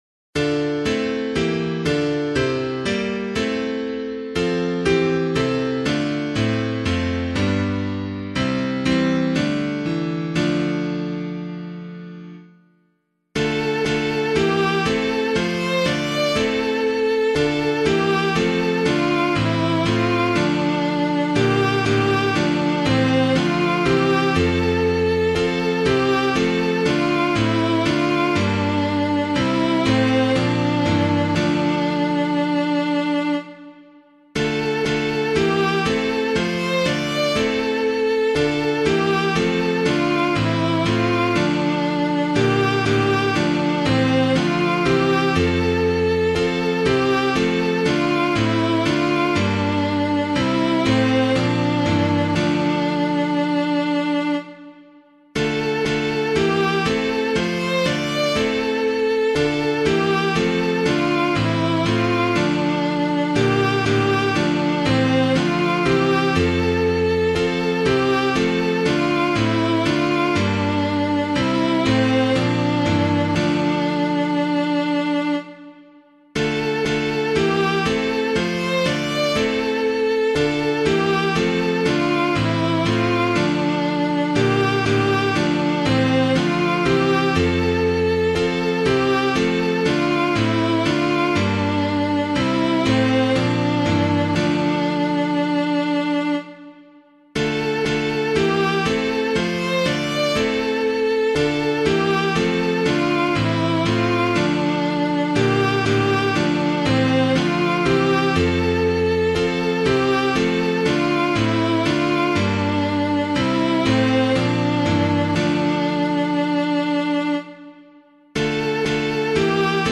piano
Christ the Lord Is Risen Again [Winkworth - CHRIST IST ERSTANDEN] - piano.mp3